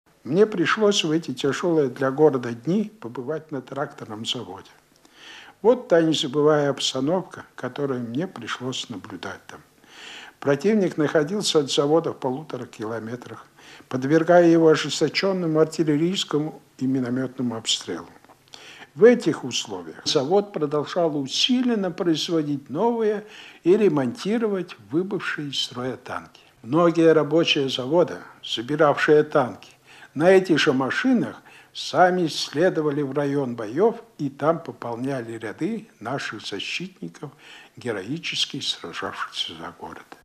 Маршал Александр Василевский вспоминает об одном из начальных этапов битвы за Сталинград – боях у Тракторного завода. (Архивная запись)